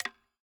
bloom_minigame_SFX_UI_Click_General.ogg